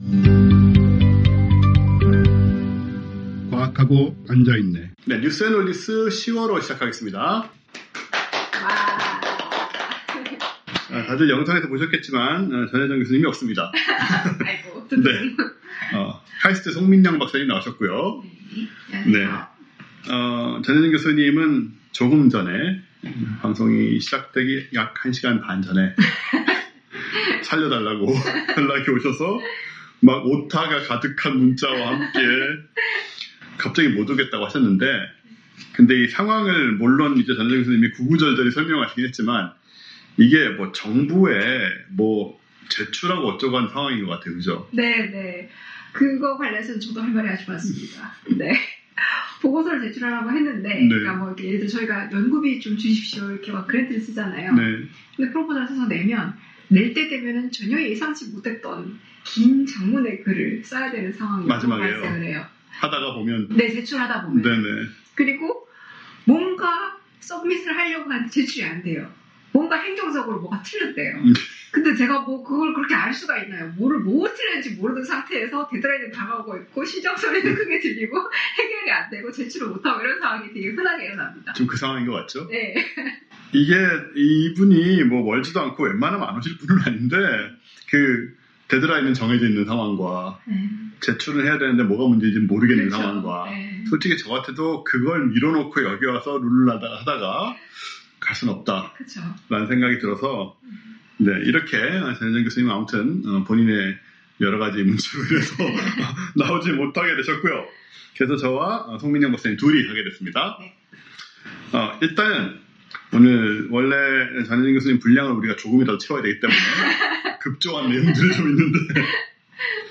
(녹음장치의 에러로 26분 경까지 음질이 좋지 못한 점, 양해 부탁드립니다)